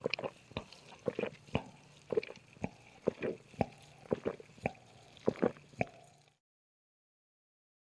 Звуки глотка